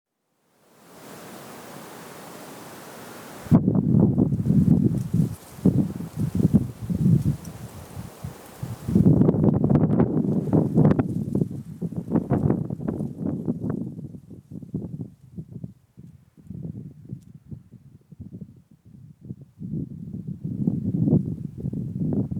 Yet, as I traversed this same location, I tried to be as silent as possible while extending my small Sony IC recorder in the air, pushing record, and appearing to capture nothing but wind gusts, audible
Given Echo Canyon’s location along Lesser Long-nosed Bat migratory routes, for example, my recording could include ultrasonic echoes of last night’s echolocation calls.